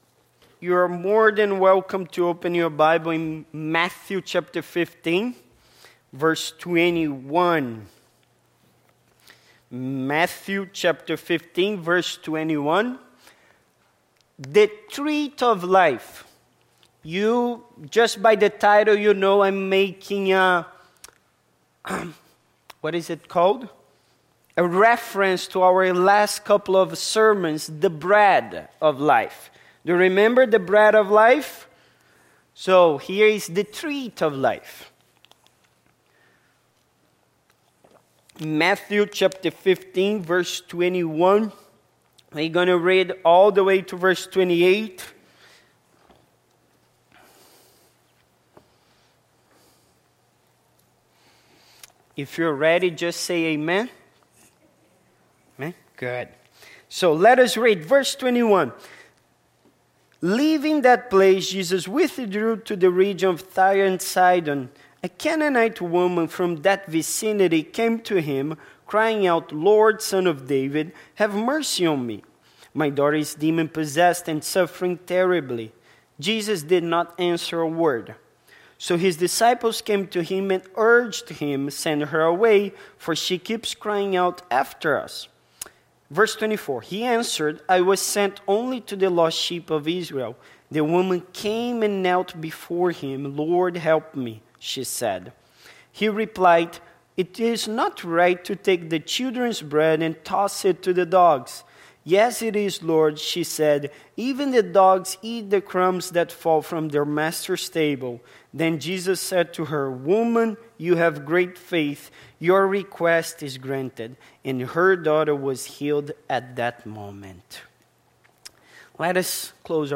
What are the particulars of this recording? Passage: Matthew 15:21-28 Service Type: Sunday Morning